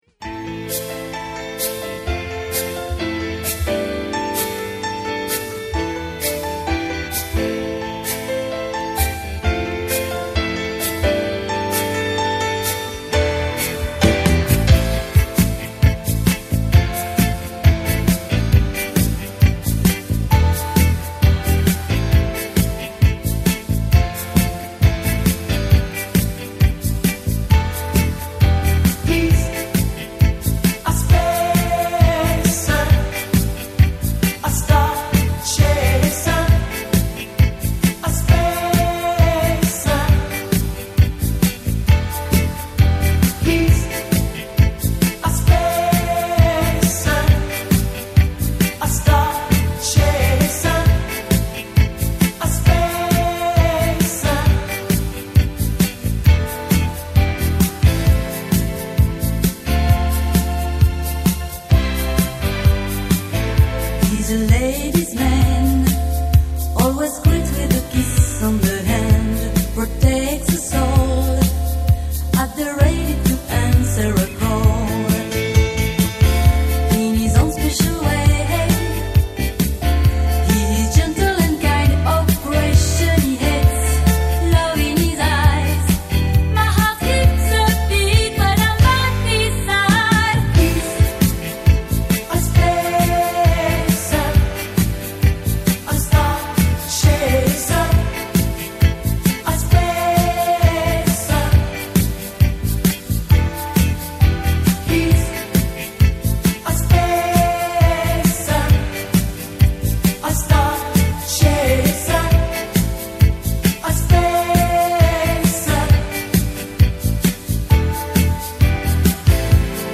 フレンチPOPを代表するディスコ・グループ